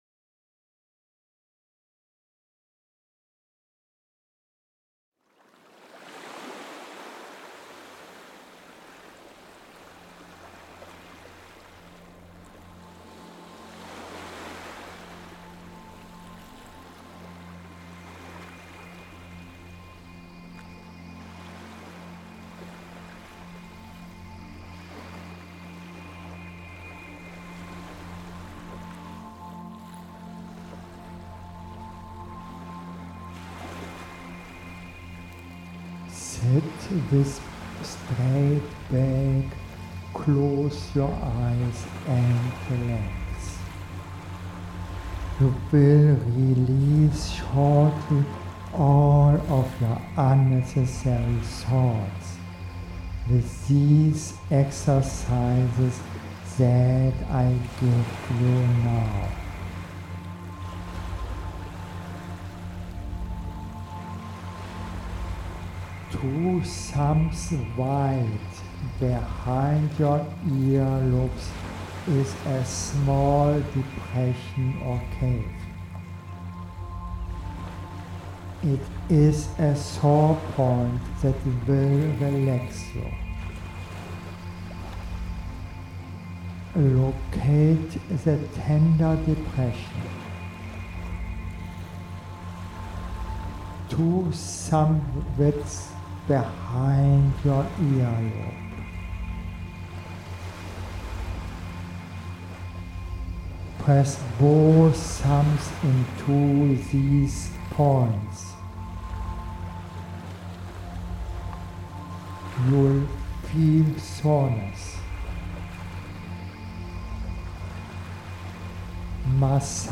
I address this with my hypnotherapy MP3.
Night Routine Before bed, listen to my hypnotherapy MP3 and simply follow my guidance.
tinnitus-with-sleep2.mp3